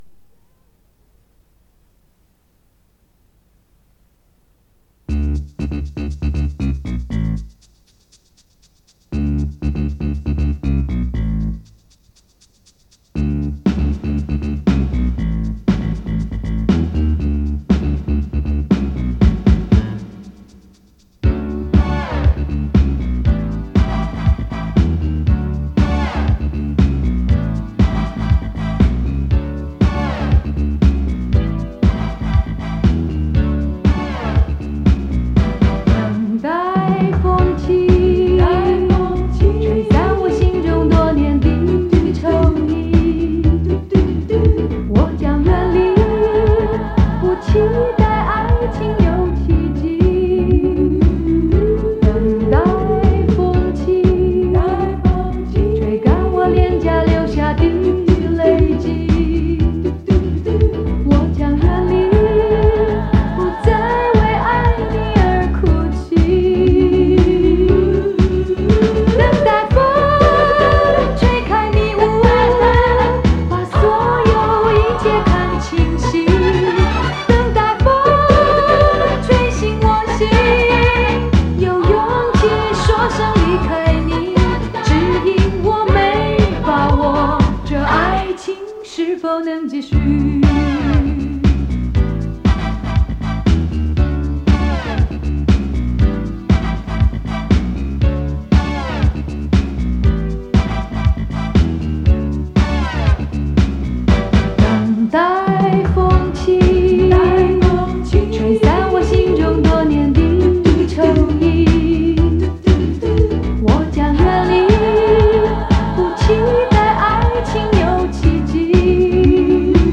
磁带数字化：2022-11-13
曲风轻松自然、挥洒自如